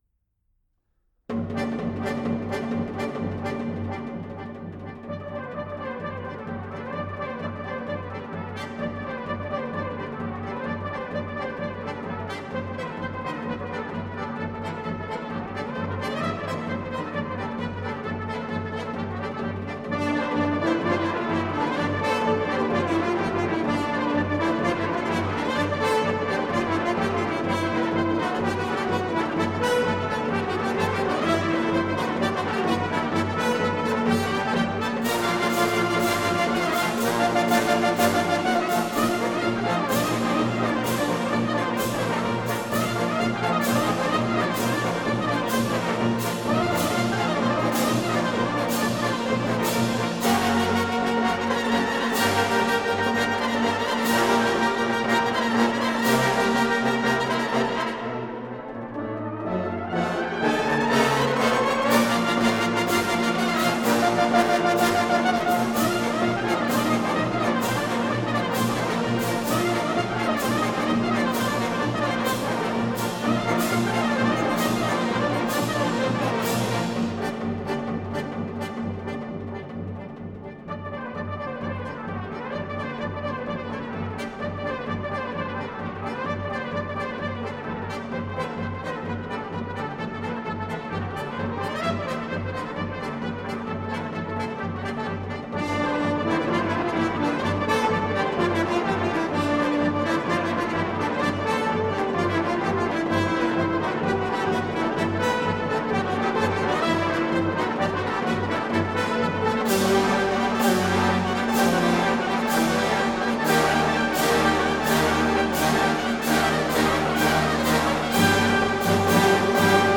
04-gayaneh_-lezghinka-(arr.-for-brass-band).mp3